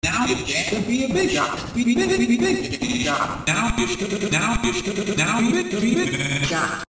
b bigshot spamton Meme Sound Effect